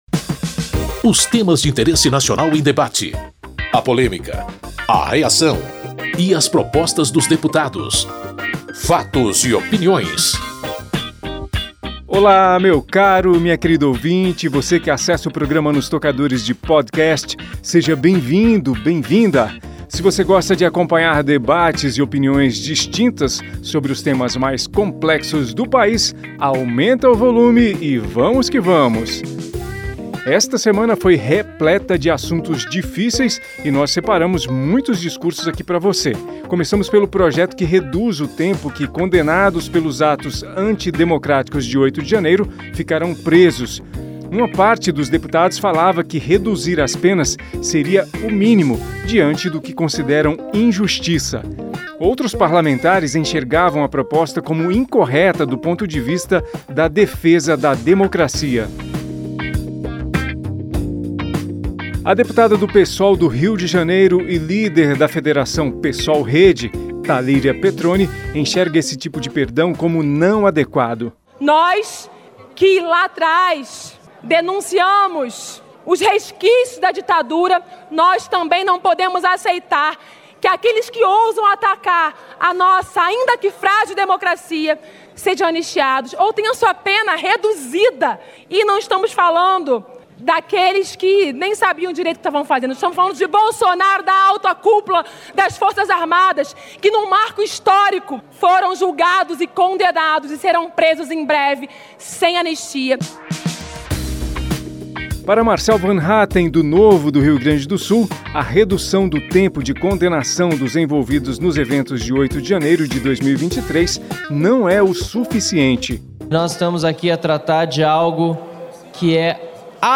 Esta semana foi repleta de assuntos difíceis e nós separamos muitos discursos para você acompanhar os debates. Começamos pelo projeto que reduz o tempo que condenados pelos atos antidemocráticos de 8 de janeiro ficarão presos (Projeto de Lei 2162/23).